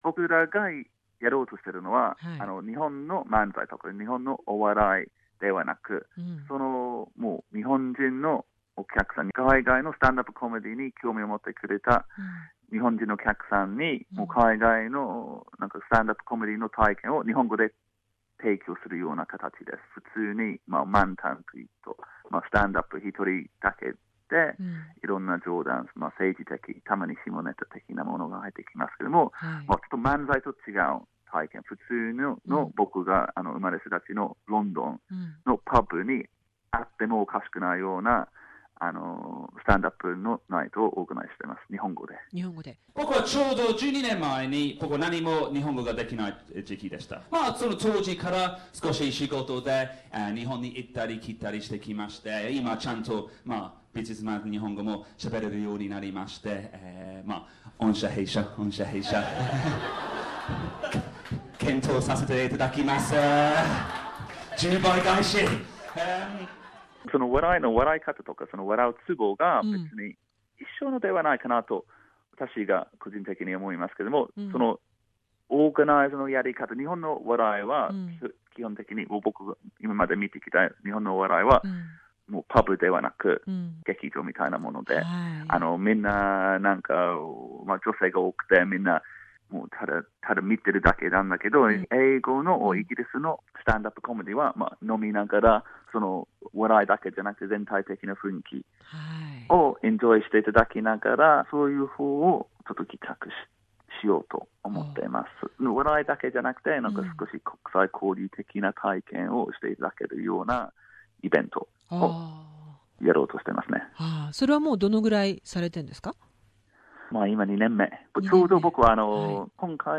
He talks about the fun and difficulties of doing stand-up in Japanese language. His actual Japanese stand-up is included in the interview.